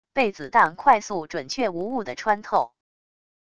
被子弹快速准确无误的穿透wav音频